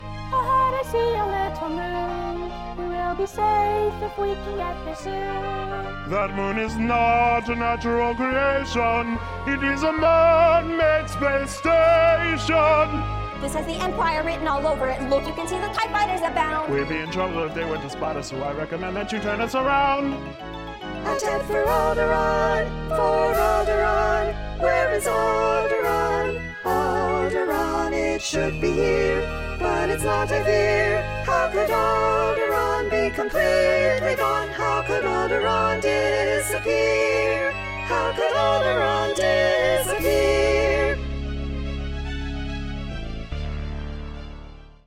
Hear bars 34-58 with all voices